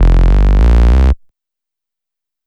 FK097BASS1-L.wav